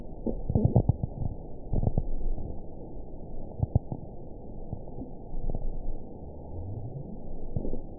event 920761 date 04/07/24 time 23:33:58 GMT (1 year, 2 months ago) score 6.30 location TSS-AB10 detected by nrw target species NRW annotations +NRW Spectrogram: Frequency (kHz) vs. Time (s) audio not available .wav